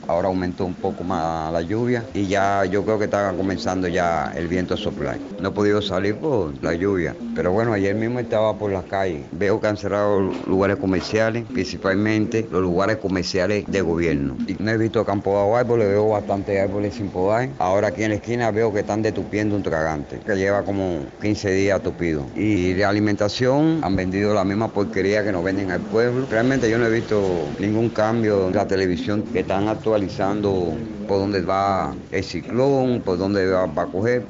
Un testimonio de Santiago de Cuba